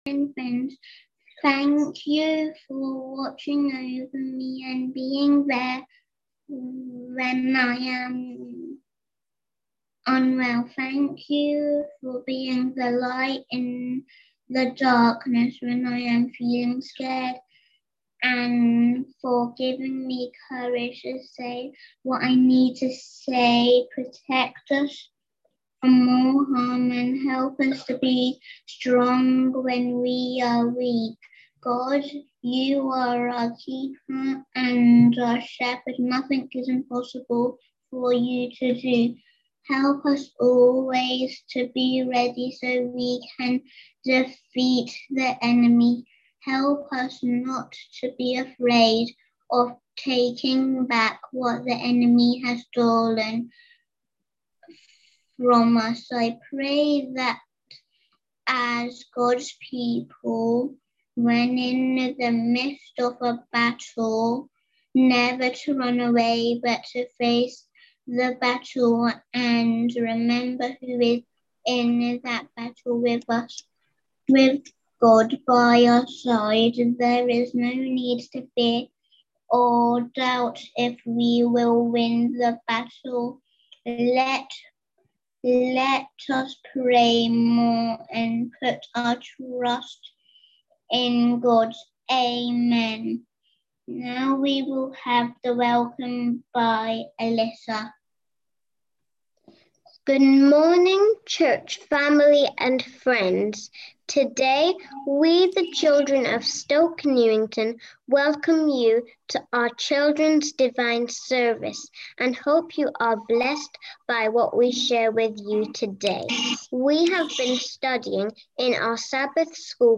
Children_s_Church_20.2.21.m4a
on 2024-02-14 - Sabbath Sermons